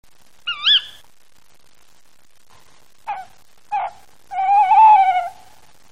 La chouette hulotte, Strix aluco.
écoutez le chant du mâle puis celui de la
femelle répondant au mâle (plus aigu).
hulotte1.mp3